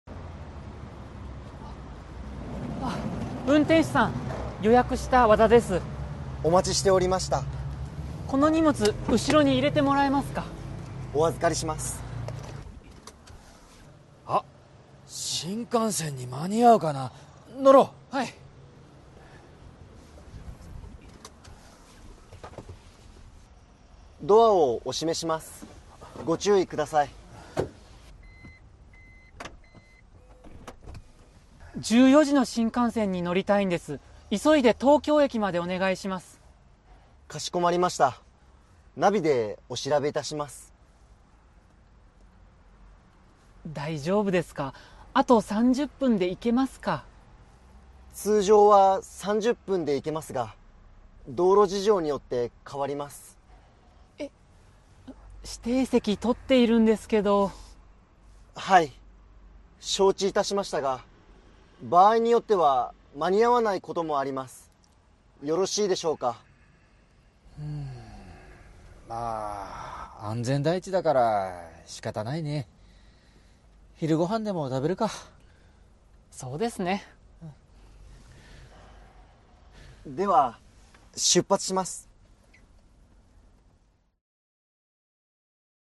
Role-play Setup
skit20.mp3